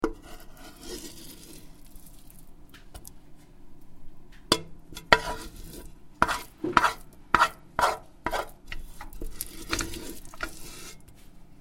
На этой странице собраны разнообразные звуки, связанные с макаронами: от шуршания сухих спагетти до бульканья кипящей воды.
Звук макарон из сковородки или кастрюли